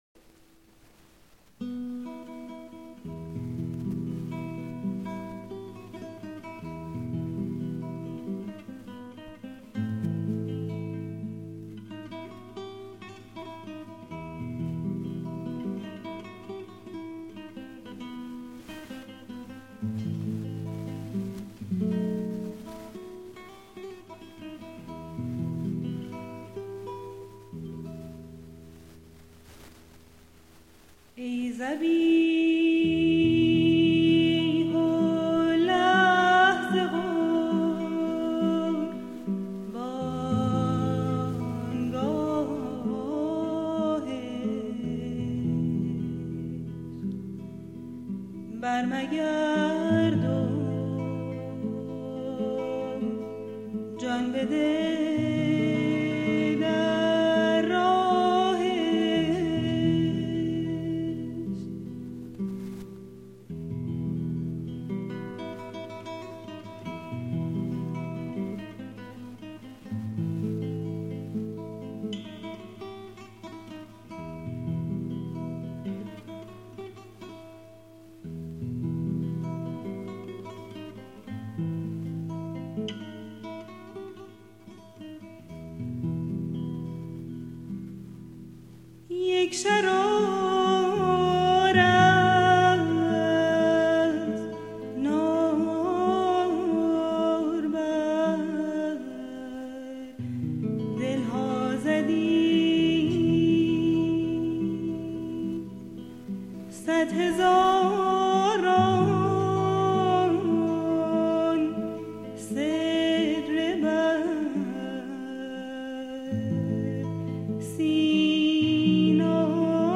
سرود - شماره 4 | تعالیم و عقاید آئین بهائی